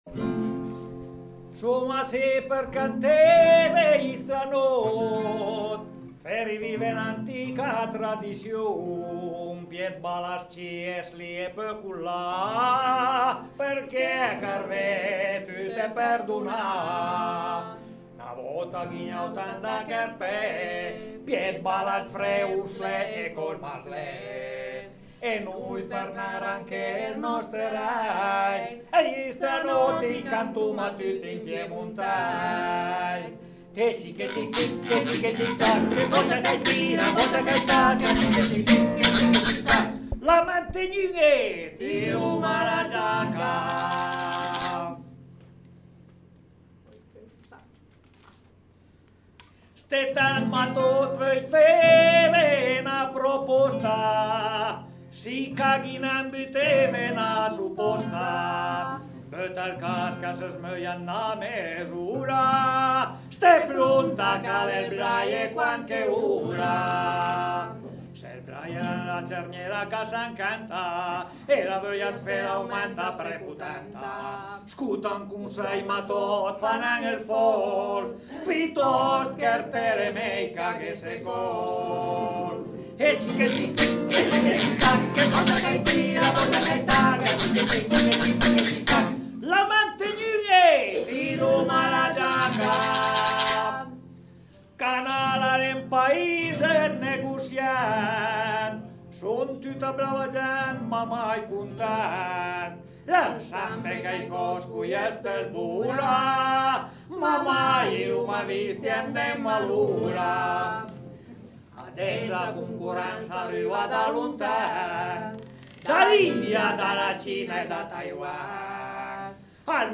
strambotti